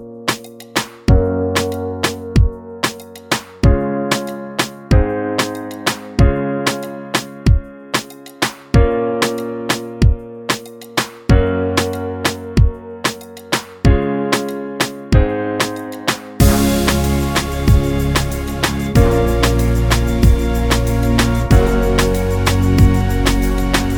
Professional Pop (2010s) Backing Tracks.